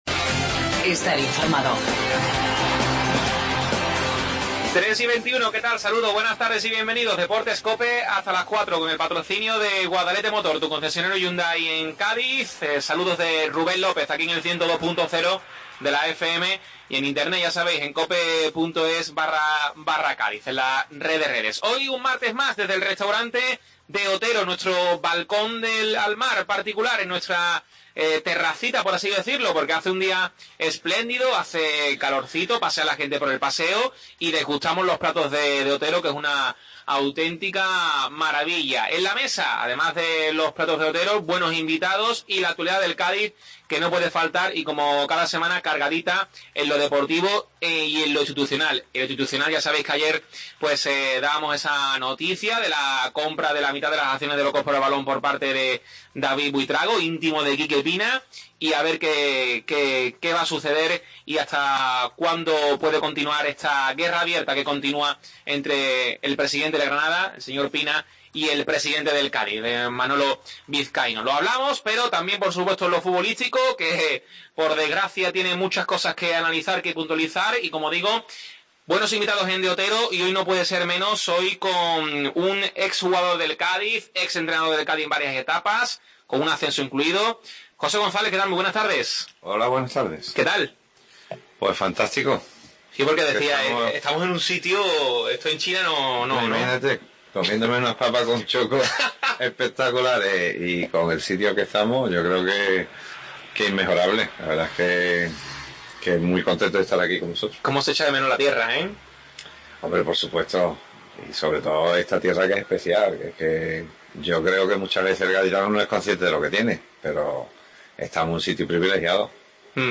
Desde el Restaurante De Otero tertulia